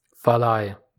Valley ([faˈlaɪ]
Aussprache/?) ist eine Gemeinde im oberbayerischen Landkreis Miesbach.